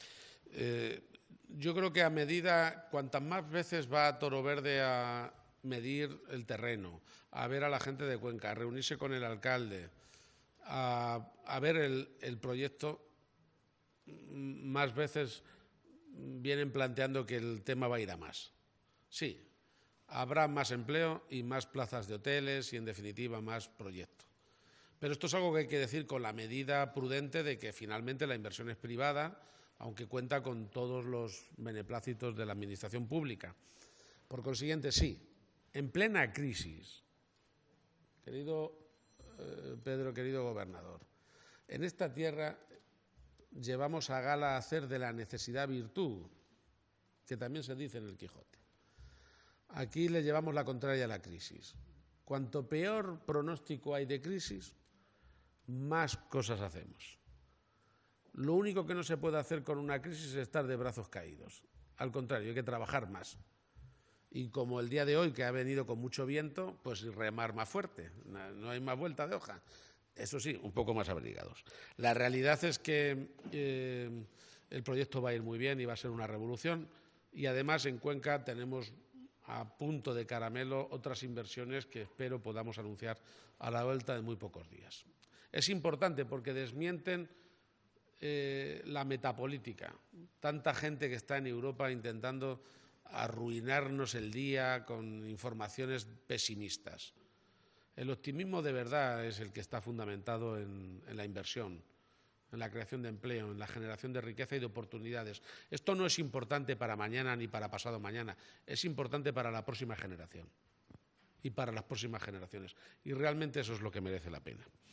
Delegación Provincial de la Junta de Comunidades de Castilla-La Mancha en Cuenca Martes, 17 Enero 2023 - 4:45pm El presidente castellanomanchego se ha referido también a los proyectos empresariales avanzados recientemente durante su última visita a Cuenca.